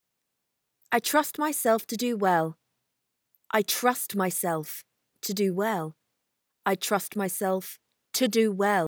Affirmation performance
1. Saying affirmations three times, each time emphasizing a different part of the affirmation.